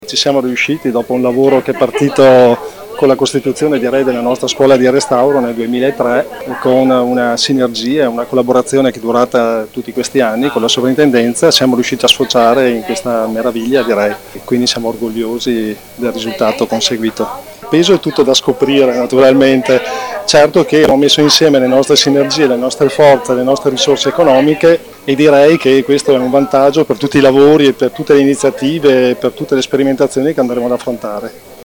Ai nostri microfoni si sono espressi alcuni dei responsabili di questo grande risultato: